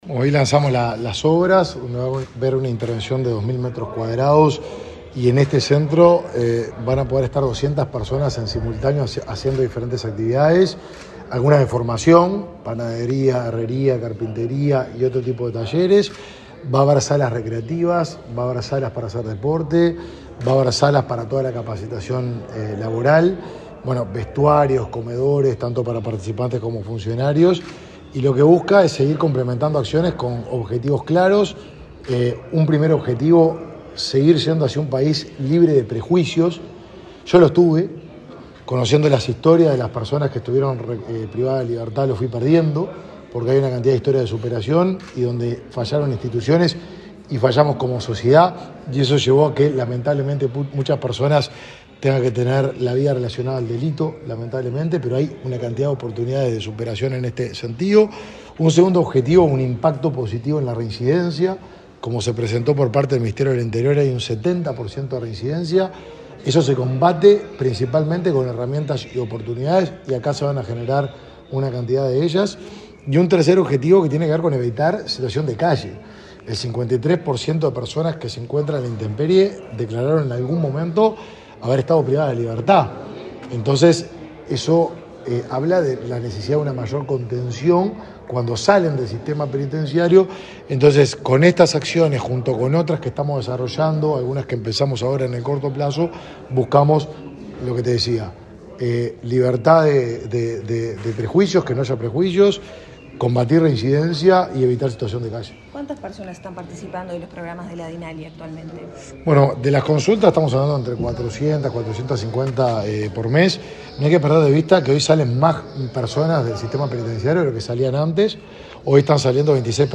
Declaraciones del ministro de Desarrollo Social, Martín Lema
El ministro de Desarrollo Social, Martín lema, dialogó con la prensa, luego de presentar junto al MTOP, el proyecto de obra del Espacio de